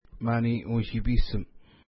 Pronunciation: ma:ni: uʃi:pi:səm